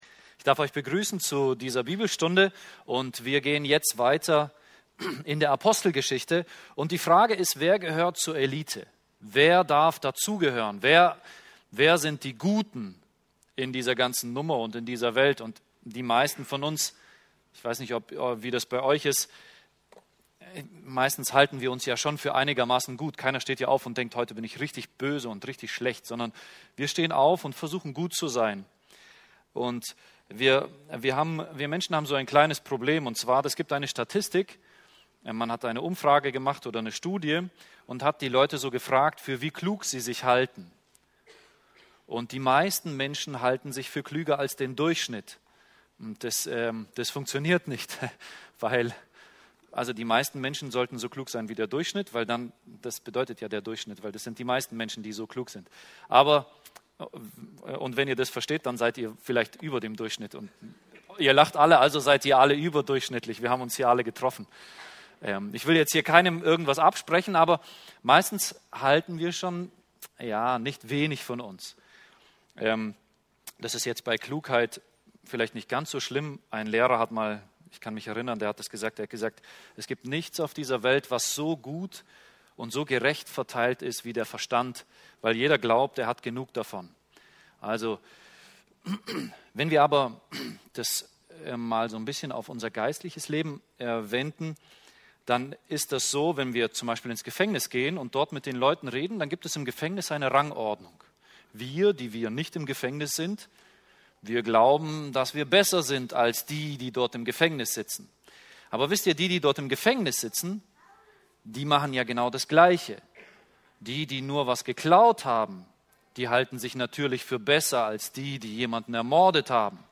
Hier findet ihr die Bibelstunden der Freikirchlichen Gemeinde Böbingen e.V.